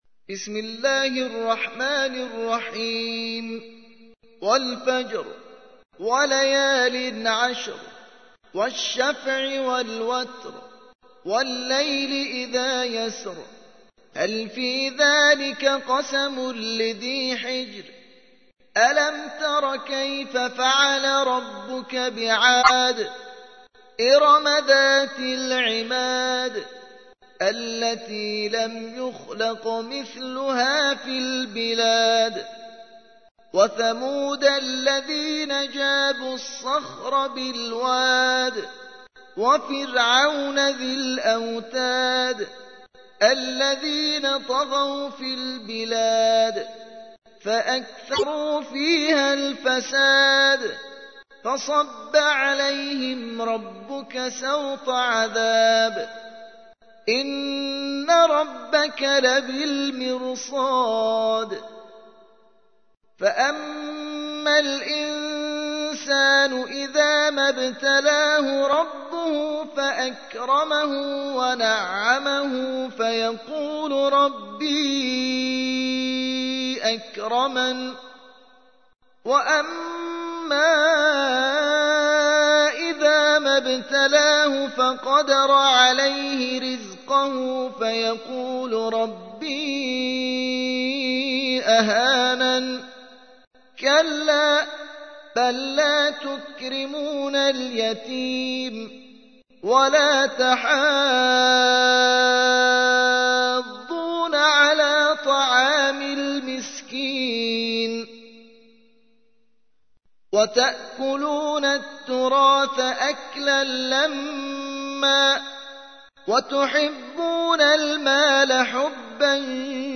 89. سورة الفجر / القارئ